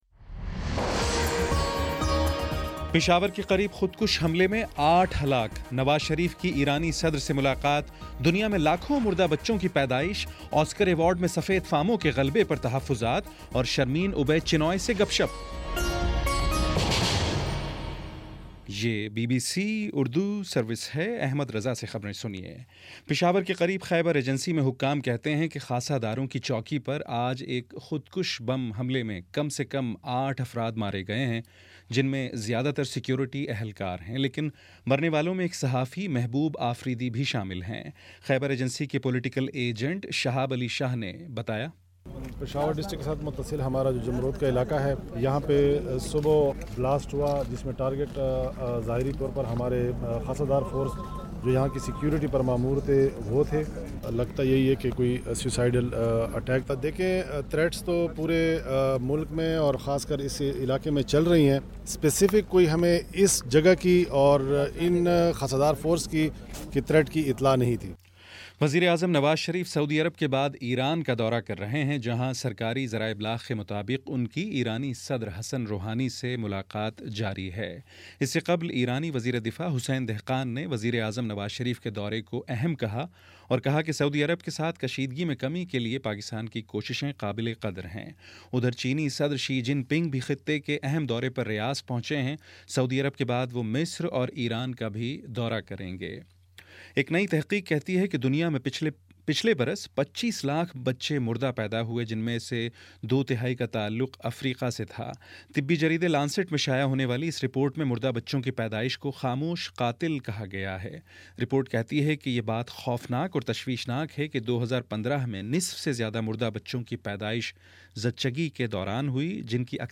جنوری 19 : شام چھ بجے کا نیوز بُلیٹن